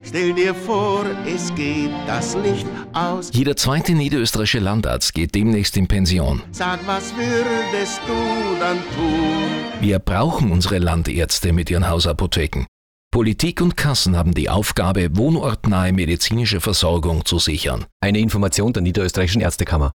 Radiospots